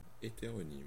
Ääntäminen
US : IPA : /ˈhɛt.ə.ɹoʊ.nɪm/ IPA : /ˈhɛt.ə.ɹə.nɪm/